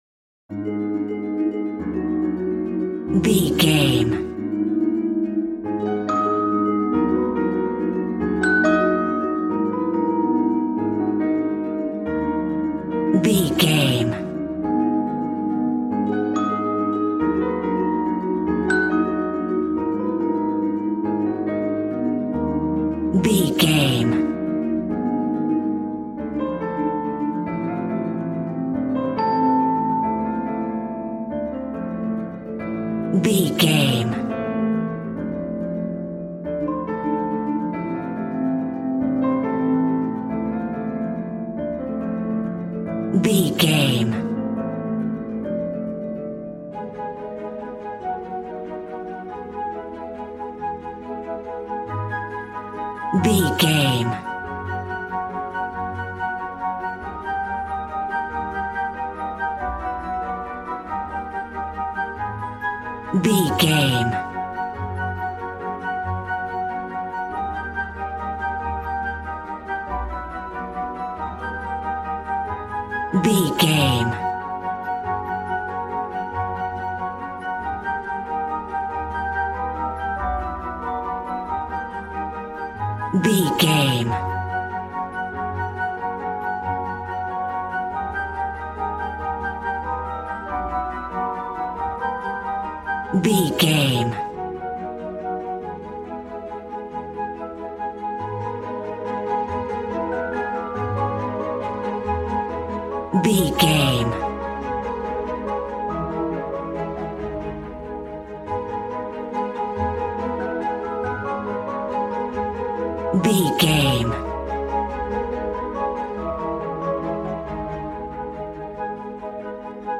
Regal and romantic, a classy piece of classical music.
Aeolian/Minor
A♭
strings
violin